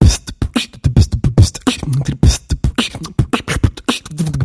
Звуки битбокса
Битовая петля для музыкального звука